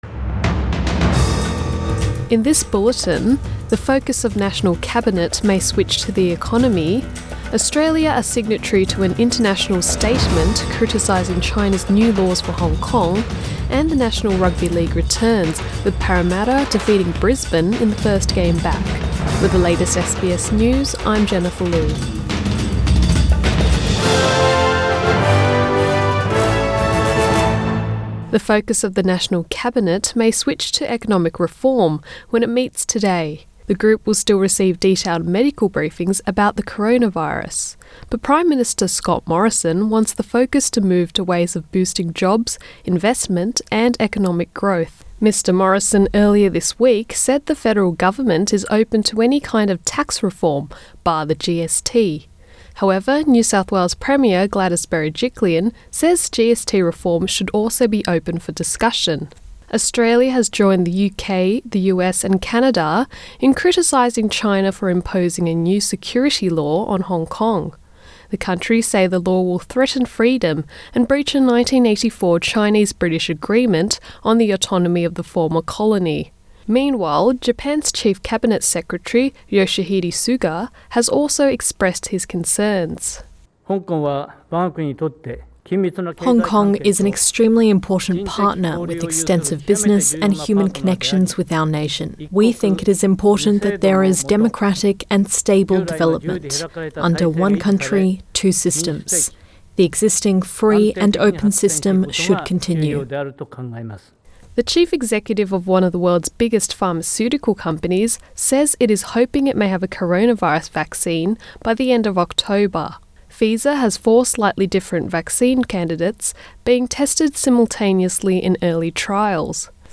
AM bulletin 29 May 2020